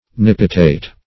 Definition of nippitate.
Search Result for " nippitate" : The Collaborative International Dictionary of English v.0.48: Nippitate \Nip"pi*tate\, a. [Cf. 1st Nip .]